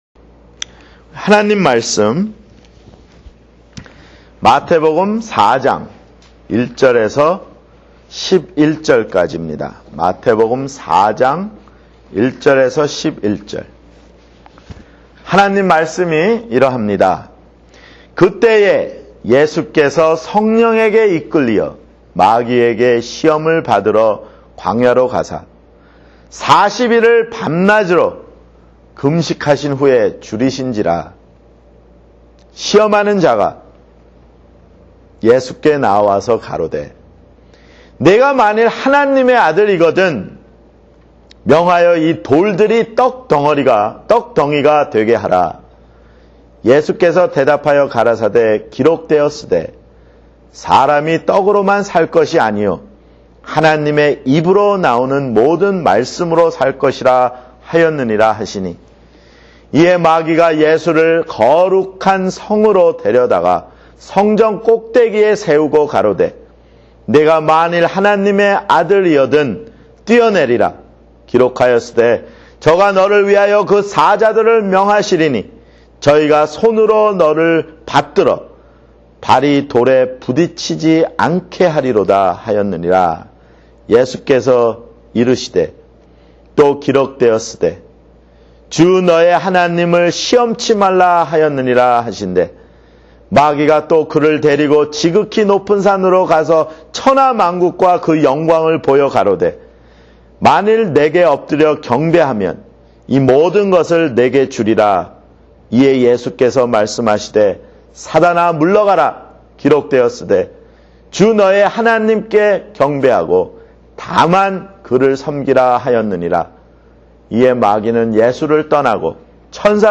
[주일설교] 마태복음 (9)